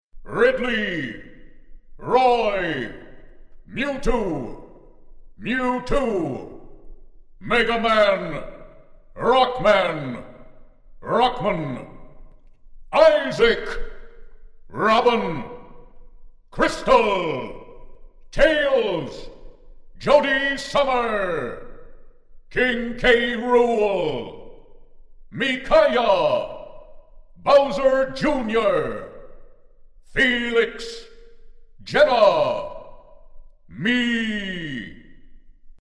Announcer.mp3